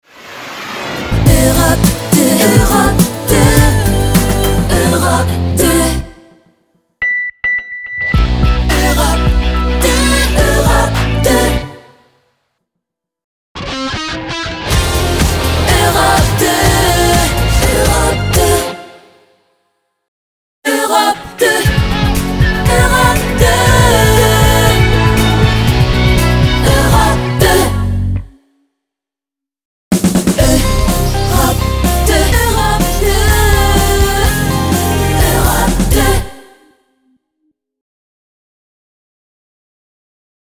Chanteuse, Choeurs
Punchy